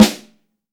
Index of /90_sSampleCDs/AKAI S6000 CD-ROM - Volume 3/Snare1/PICCOLO_SN